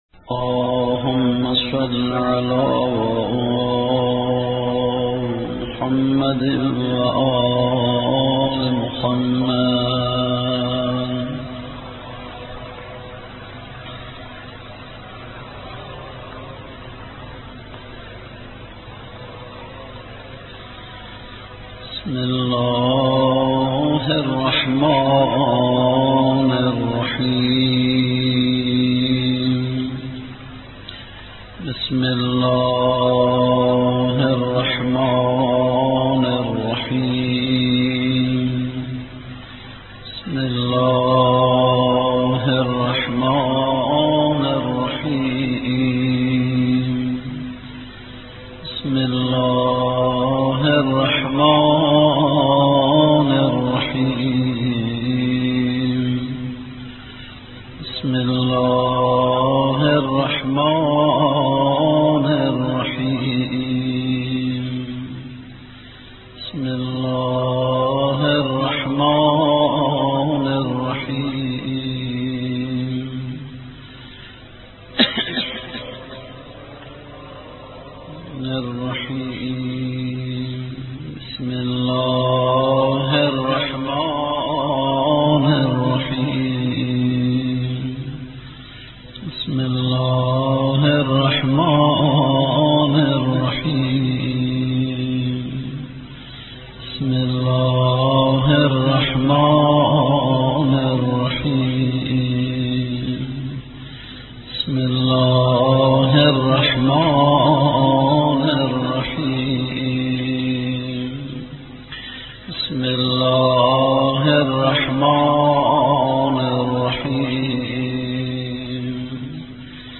دعای ابوحمزه ثمالی با صدای دلنشین